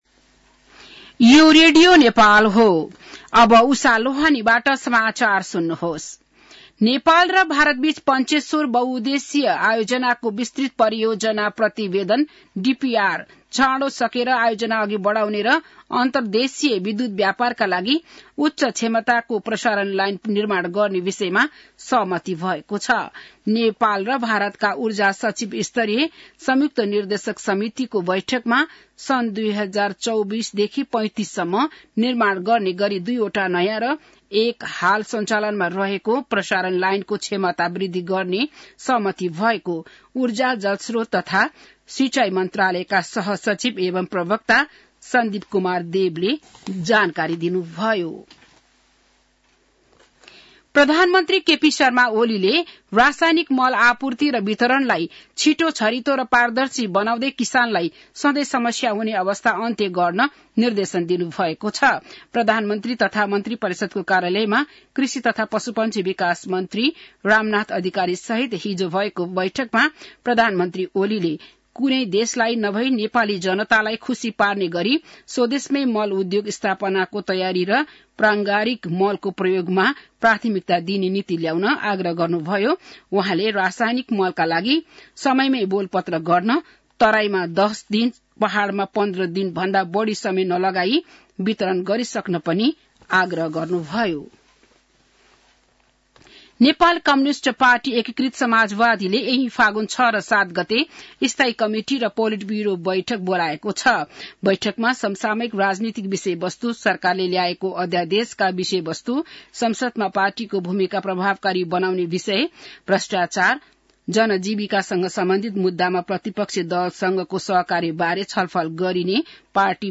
बिहान १० बजेको नेपाली समाचार : ३ फागुन , २०८१